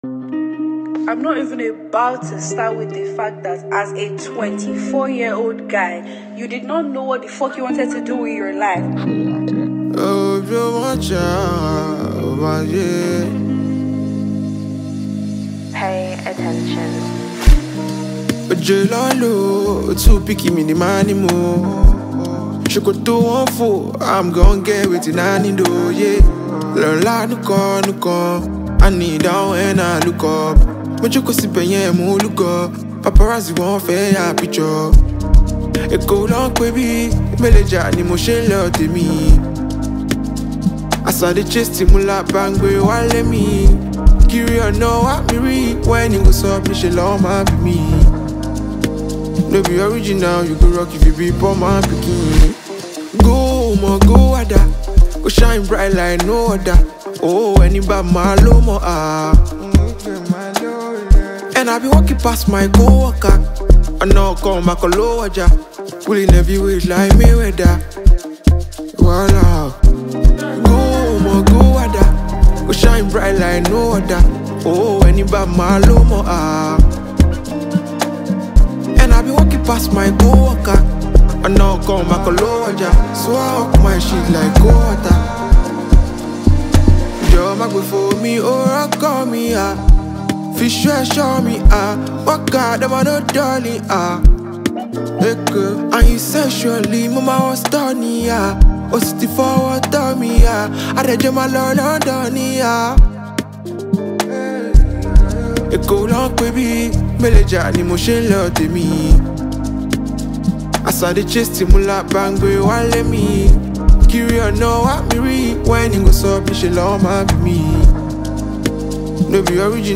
Nigerian phenomenal singer-songwriter